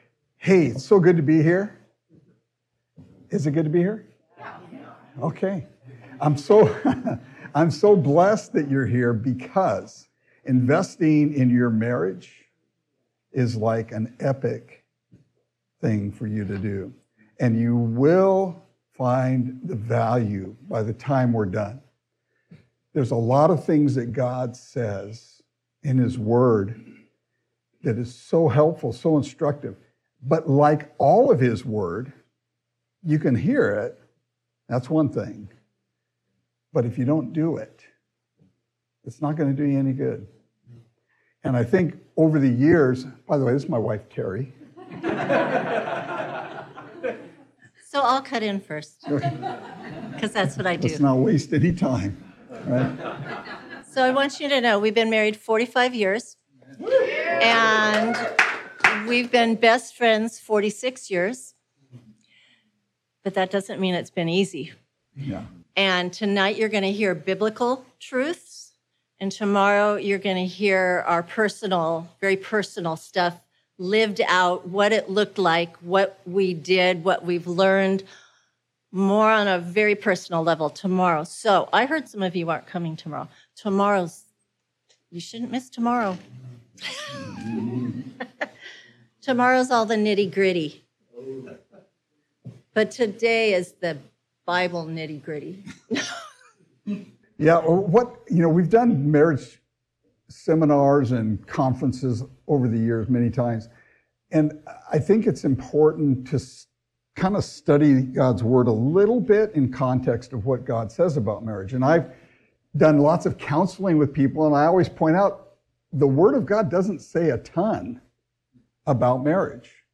Sermons | Coastline Christian Fellowship
Marriage Retreat 2025